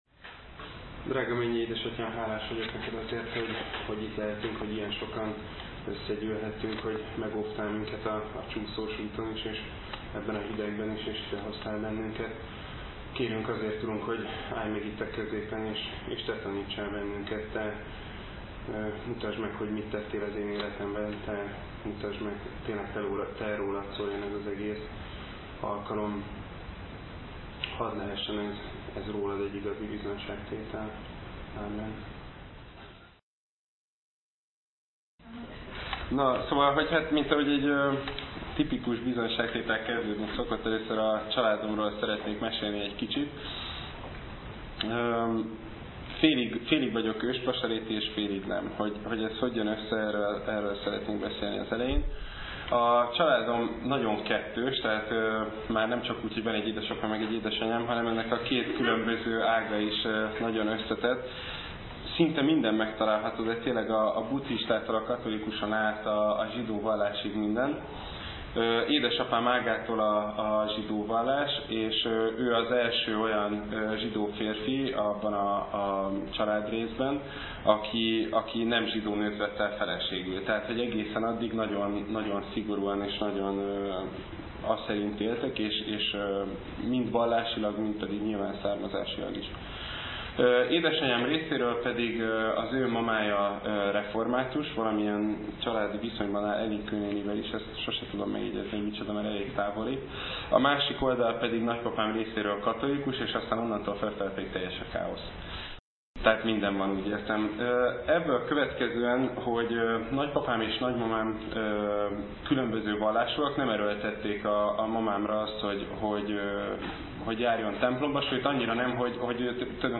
Bizonyságtétel